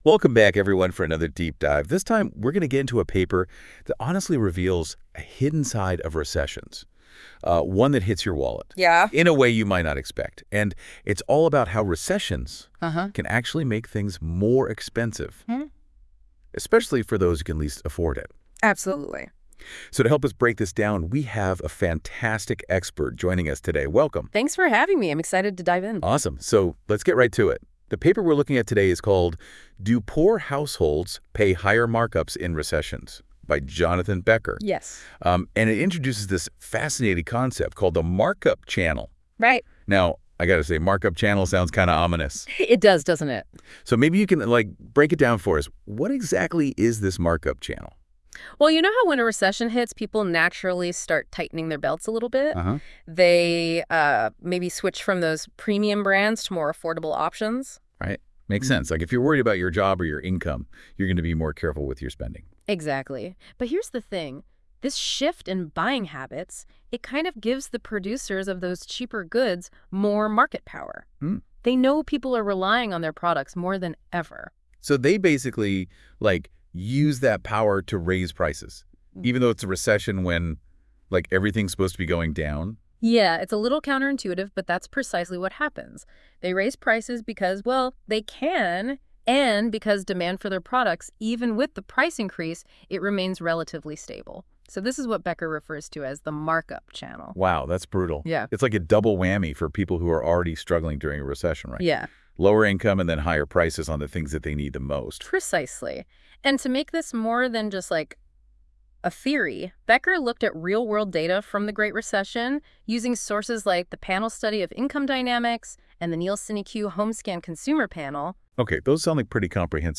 AI-generated Short Podcast ]